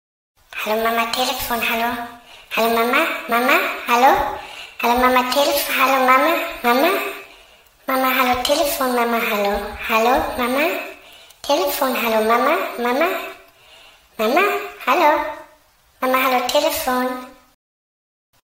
Kategorie: Klingeltöne